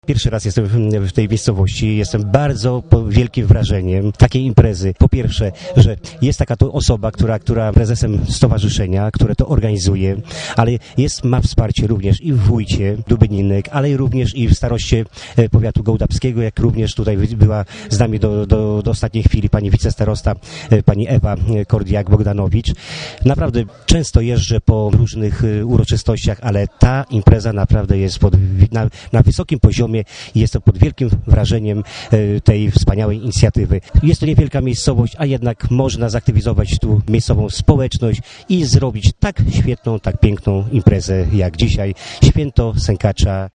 o Święcie Sękacza mówi senator Marek Konopka